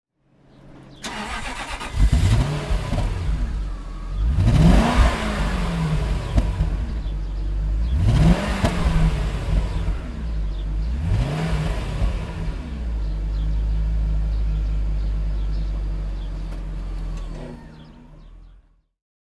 Jaguar XK8 (1997) - Starten und Leerlauf